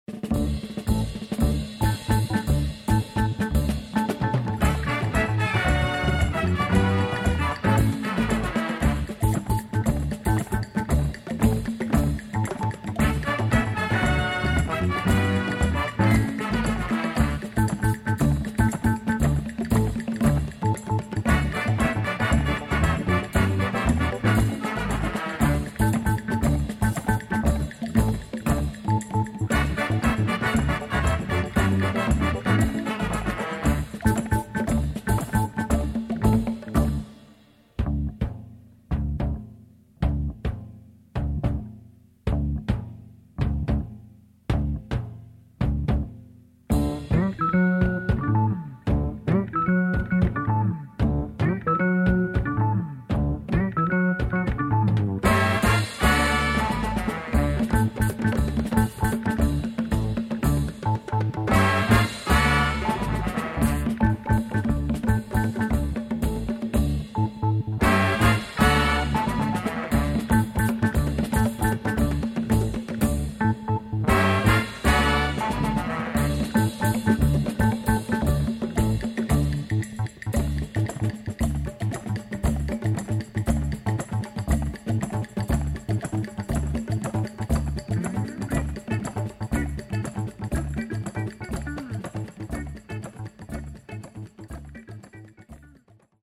Afro、Funk、Jazz、Calypso、Mentなど様々な音楽を消化したオリジナリティ溢れる傑作。